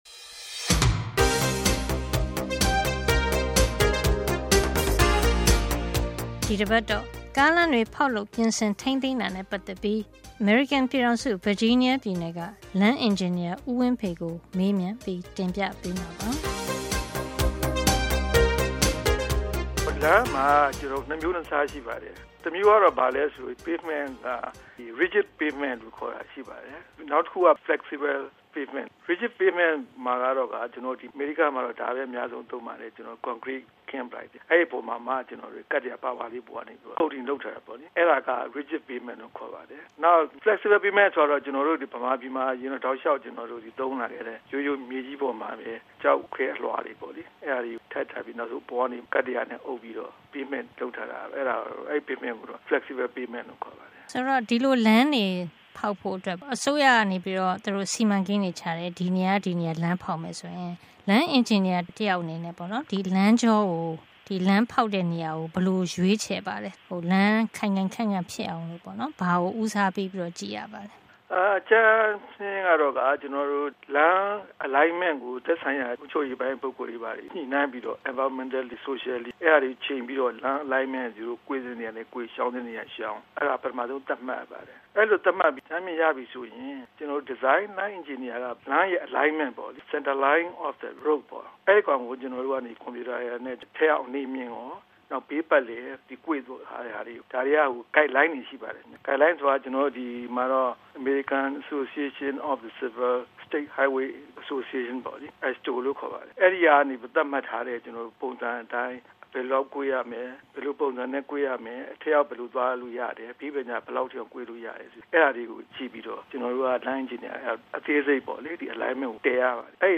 မေးမြန်း တင်ပြထားပါတယ်။